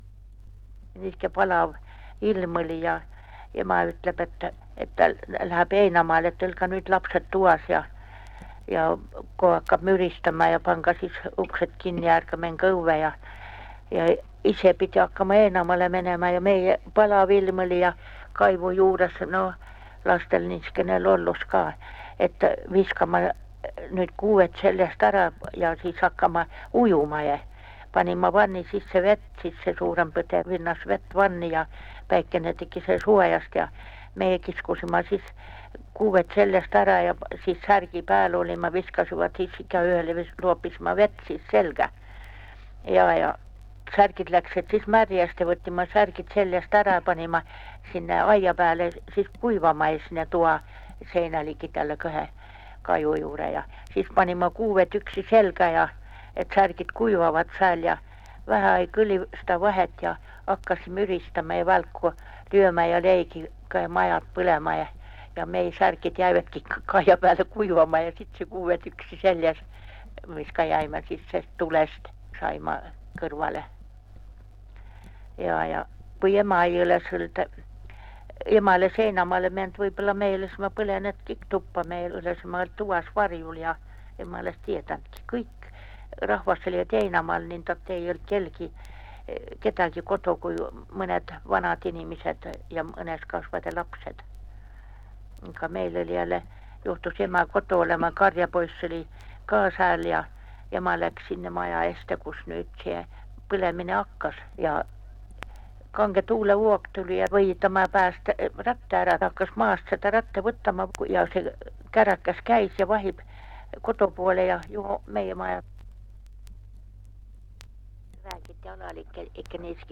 MurdekiikerKirderannikumurreRLüganuse